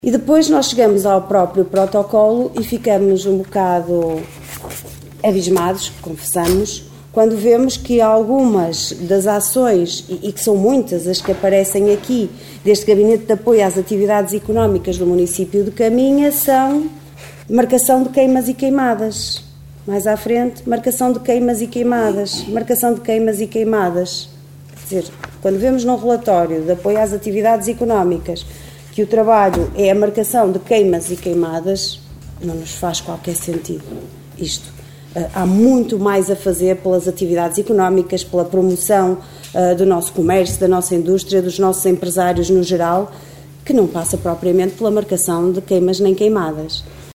Reuniao Camara Liliana Silva 21 Fev 2024